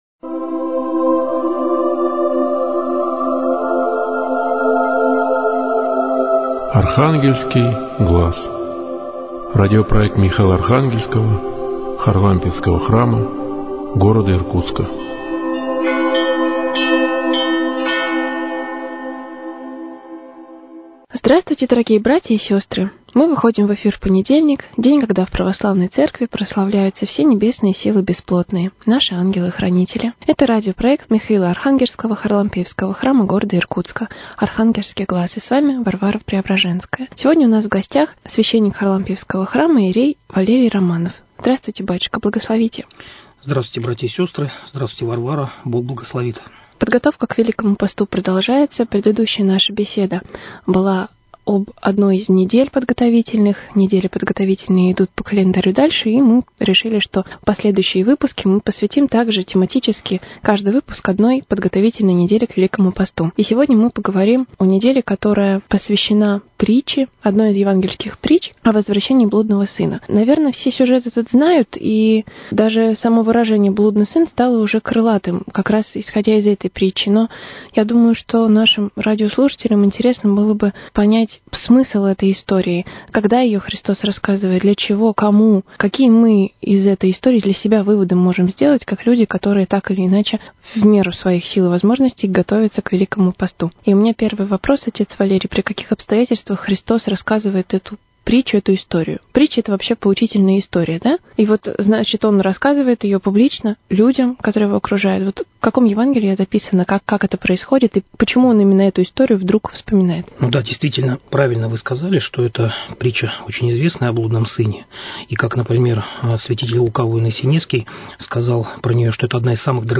Но в диалоге она оживает новыми, неожиданными гранями, заставляя задуматься о самом главном: